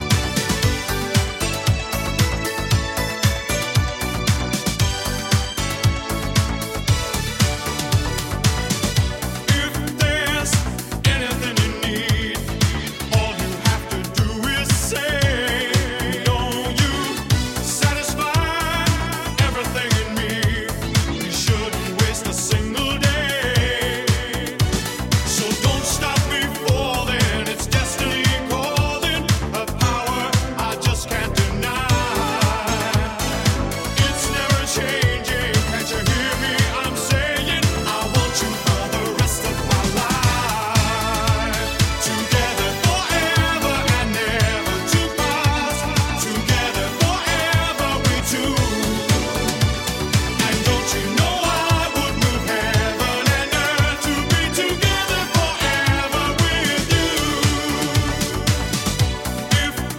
На этом диске хиты POP исполнителей!